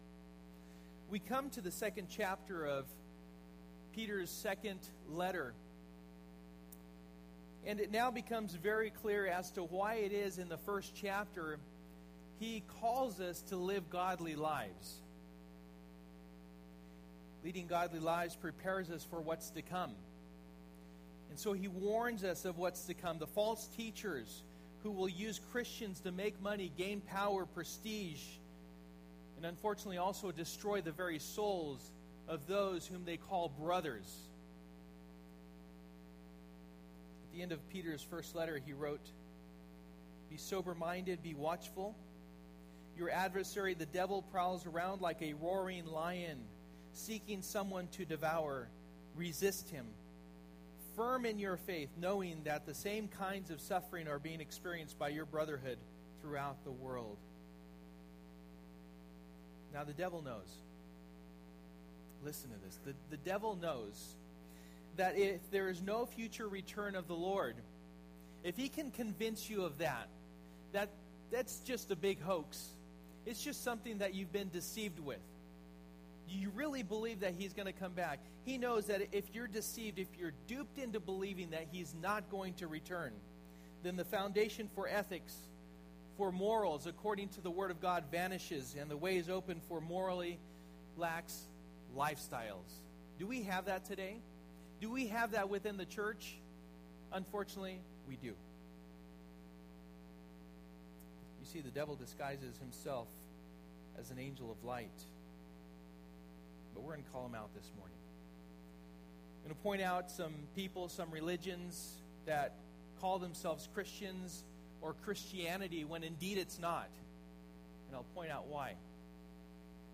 Stand Fast Passage: 2 Peter 2:1-22 Service: Sunday Morning %todo_render% « Stand Fast